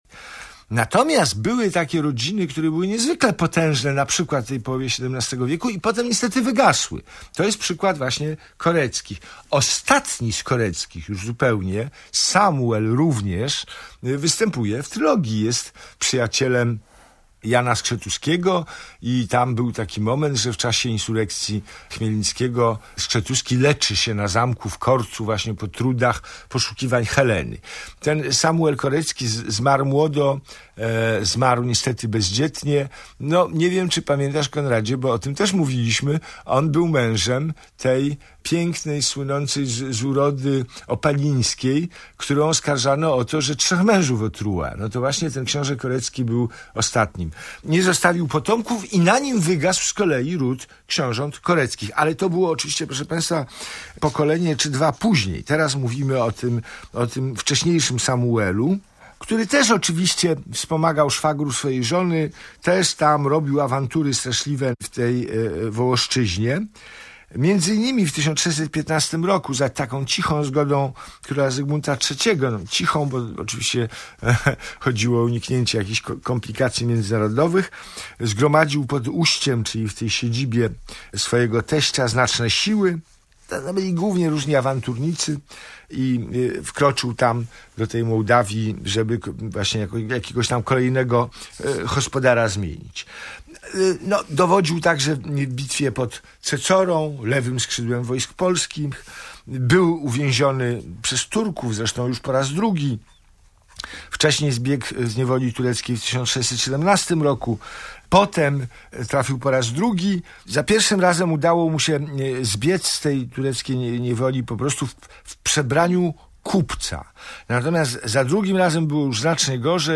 Wśród naszych słuchaczy jest wielu poetów! Przysyłacie do nas swoje wiersze co tydzień, a my czytamy je podczas audycji.